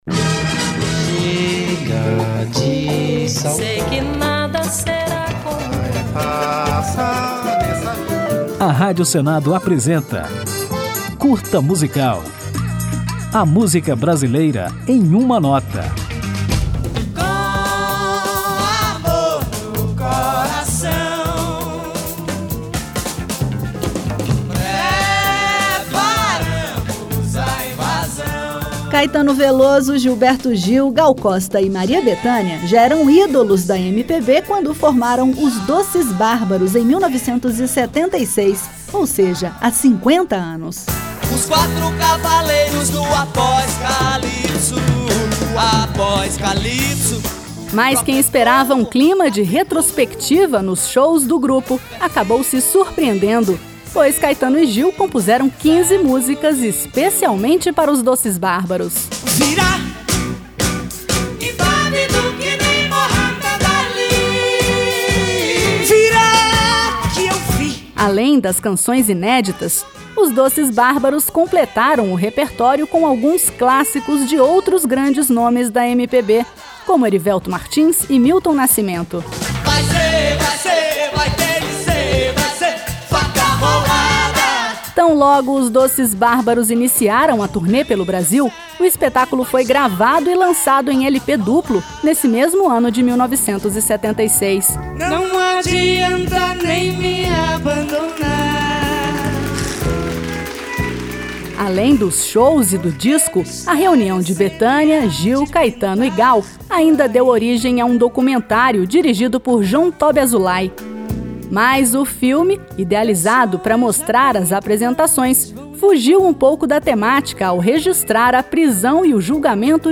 Em 1976, ou seja, há 50 anos, surgiu Os Doces Bárbaros, supergrupo formado por Maria Bethânia, Gal Costa, Caetano Veloso e Gilberto Gil. Então, aperte o play neste Curta Musical para saber tudo sobre essa lendária reunião de maiorais da MPB e ainda ouvir Os Doces Bárbaros na música O Seu Amor.